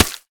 latest / assets / minecraft / sounds / block / mud / break6.ogg